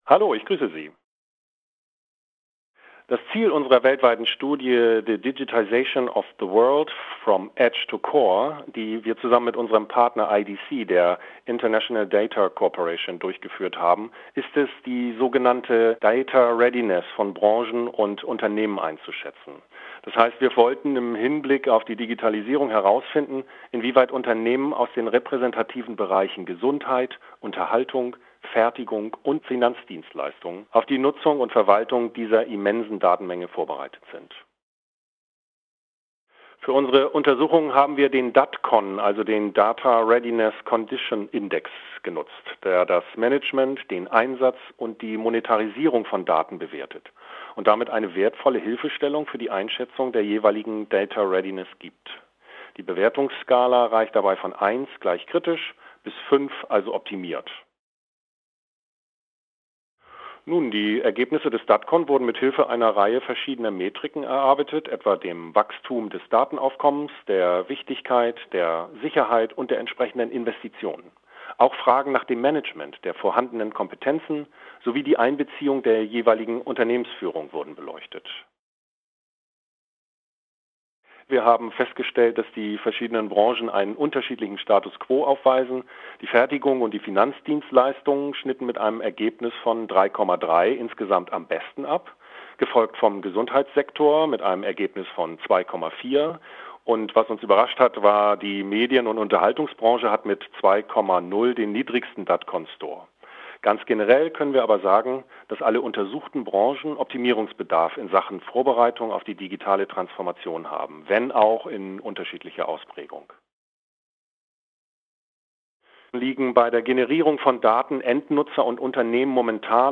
Manuskript zum Interview